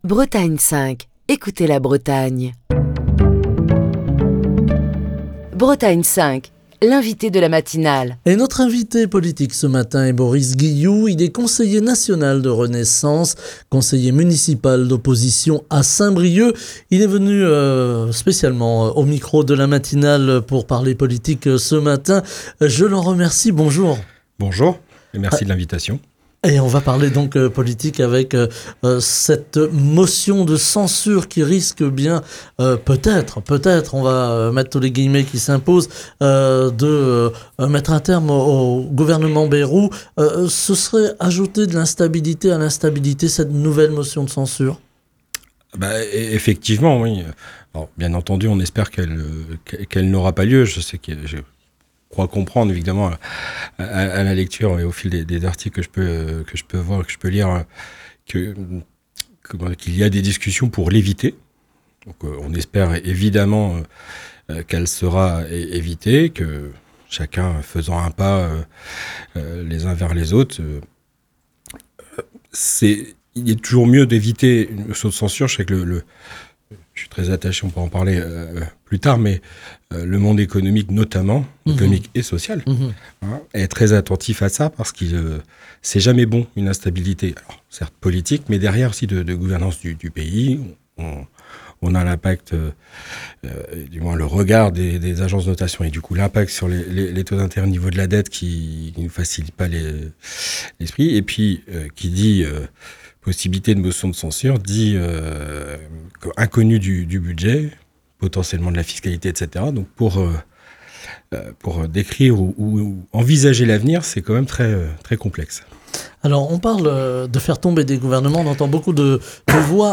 Boris Guillou, conseiller national du parti Renaissance et conseiller municipal d’opposition à Saint-Brieuc, était l’invité politique de la matinale de Bretagne 5. Boris Guillou a analysé la situation politique actuelle, marquée par une tension croissante autour de la possible motion de censure que les oppositions de gauche envisagent de déposer si le gouvernement ne suspend pas sa réforme des retraites.